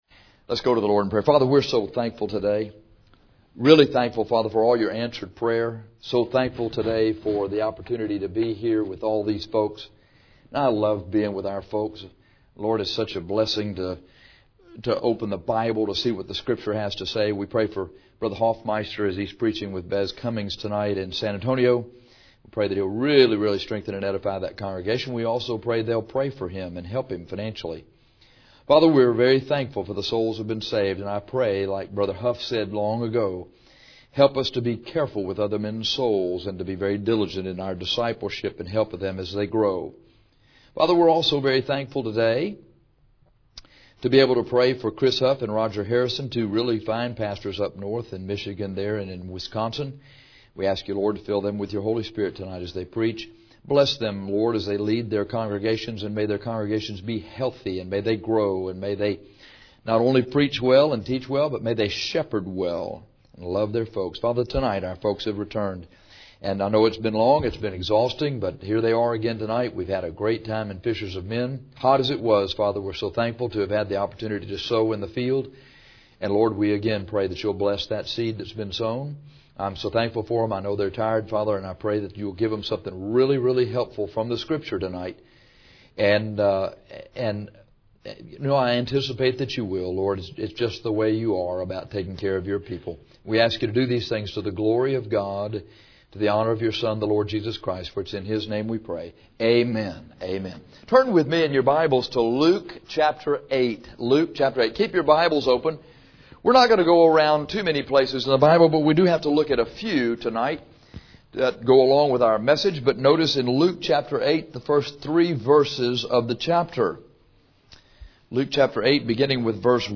Dealing with Baggage is a sermon to help you deal with your “baggage” from the past and to help you quit the sins that are associated with it.